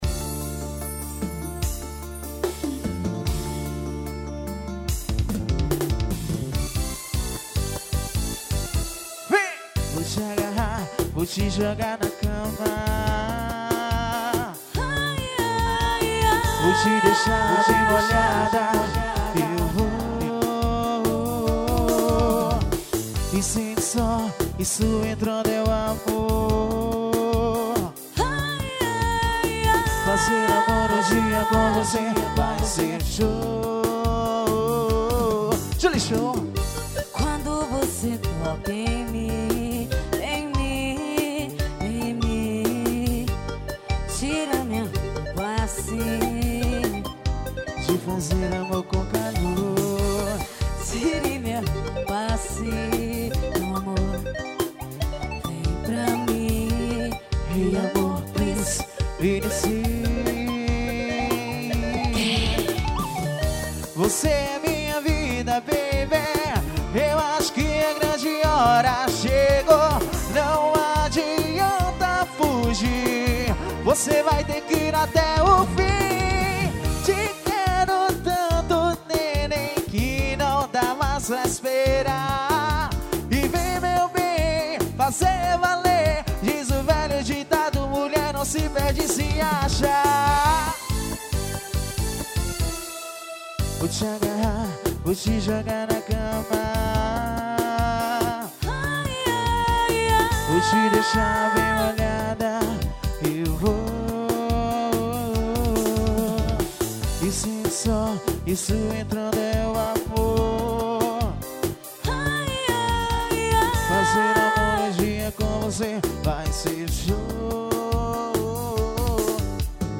Composição: Ao Vivo.